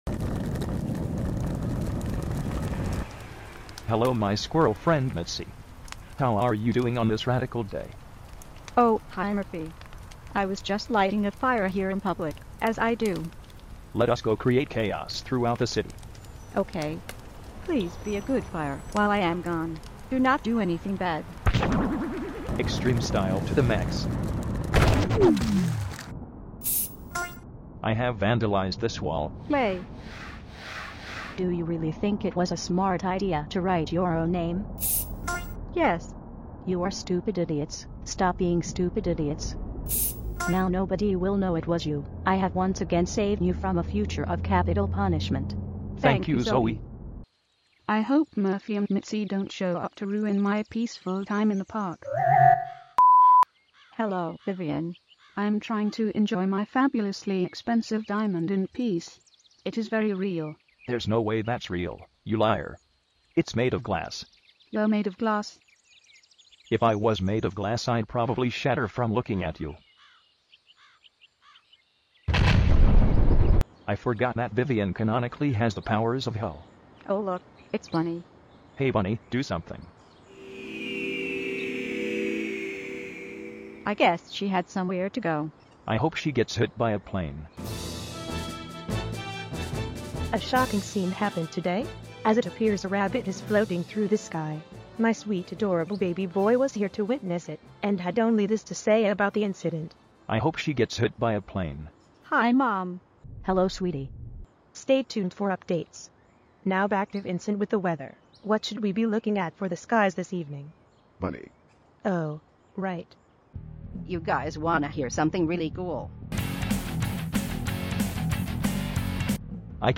Fully Voiced Short